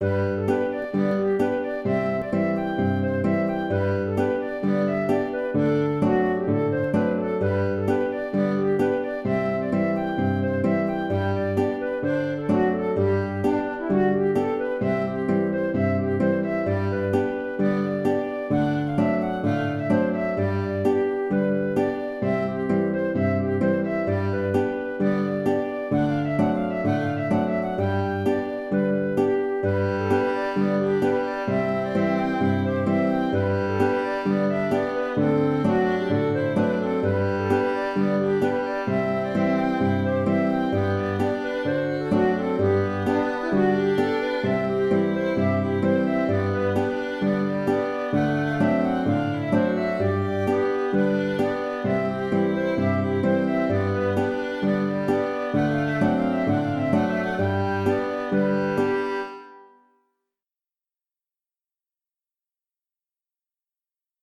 Madison
Ce qui l’est, c’est surtout le punch à mettre à l’interprétation (de mon thème ou d’un autre), ce que mon enregistrement numérique ne peut évidemment pas rendre.